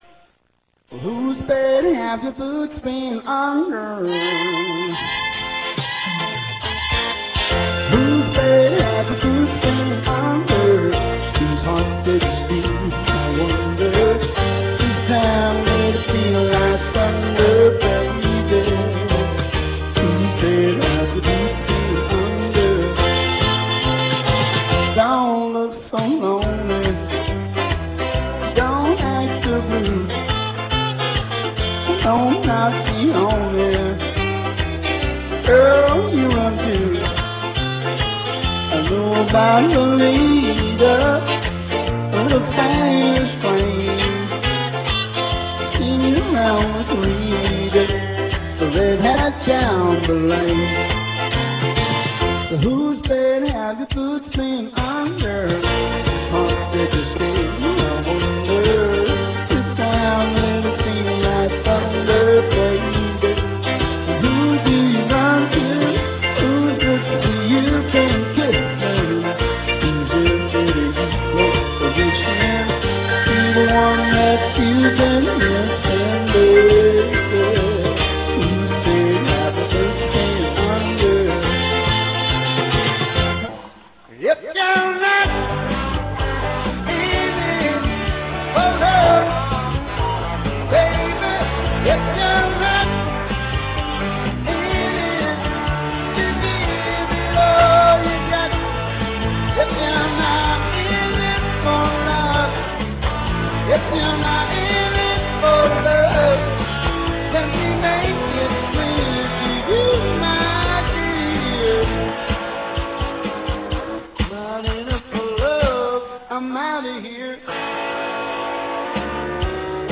* * *Country Rock* * * live guitar and vocals